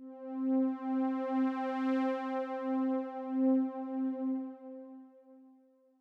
MOO String C3.wav